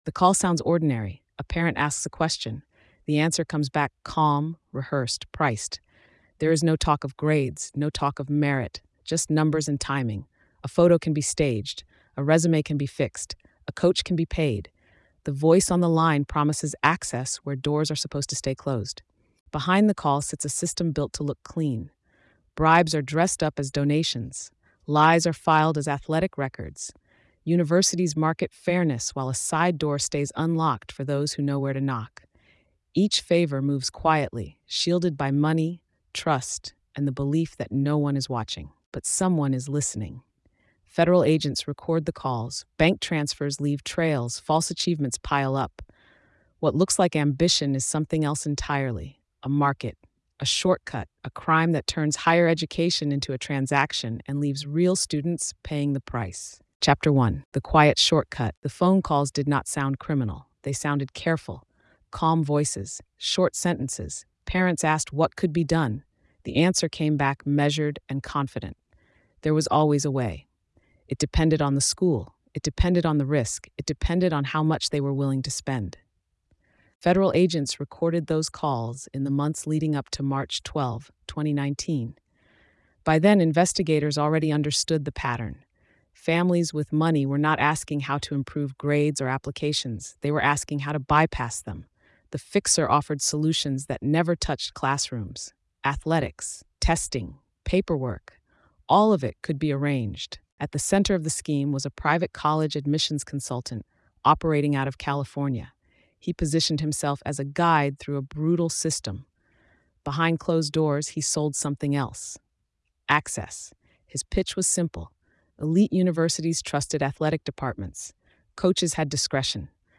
Behind polished brochures and promises of merit, a covert network allowed parents to buy influence, fabricate athletic talent, and manipulate standardized testing. Federal wiretaps, financial trails, and falsified records revealed a marketplace where admission to elite universities became a transaction. Told with a forensic, grounded tone, the story traces how trust was exploited, rules were bent, and fairness was treated as optional by those who could afford another path.